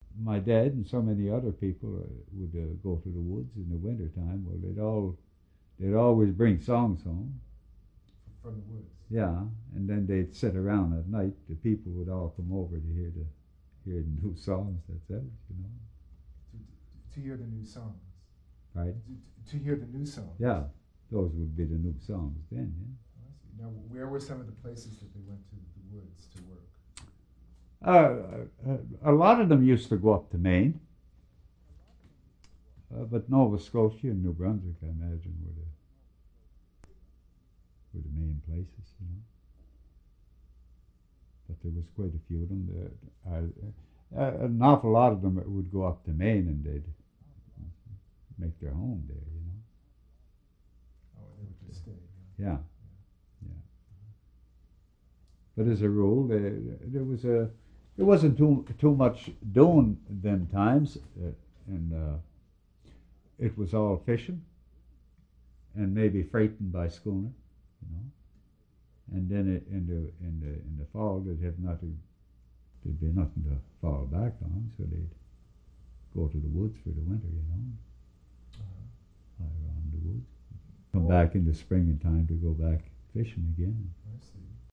vocal) - songs from the lumber woods